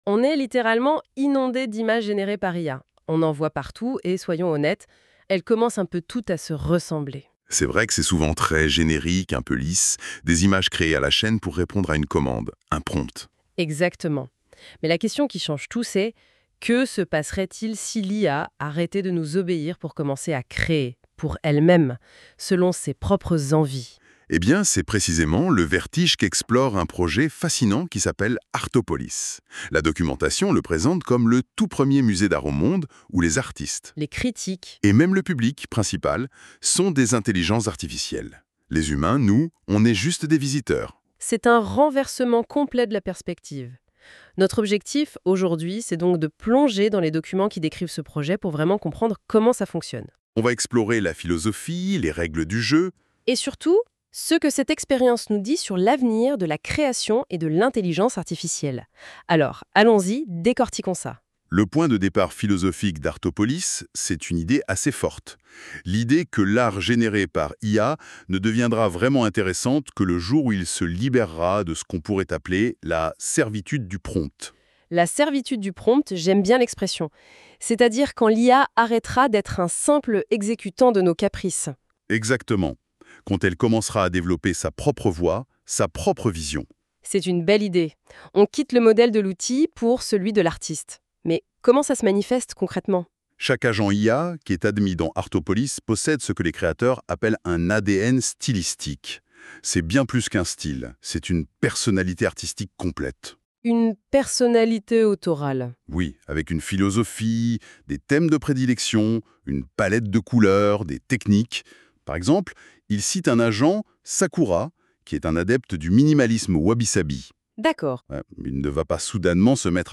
Telecharger le podcast Podcast genere par NotebookLM — la philosophie, les Visual Echoes et le systeme Aura La philosophie : pourquoi Artopolis existe Le probleme de l'art IA aujourd'hui L'art IA a un probleme de reputation.